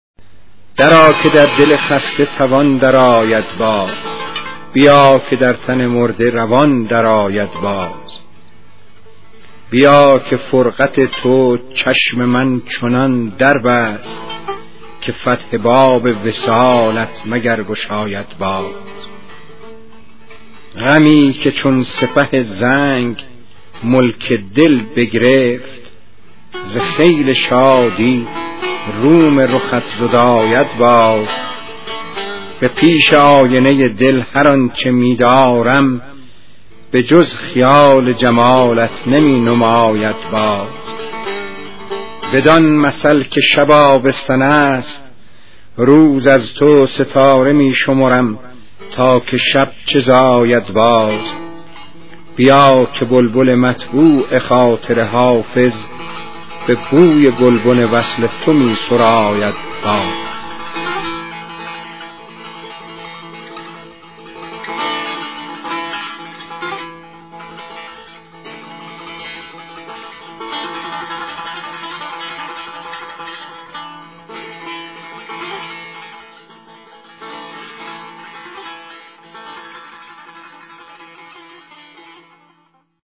خوانش غزل ۲۶۱ با صدای استاد علی موسوی گرمارودی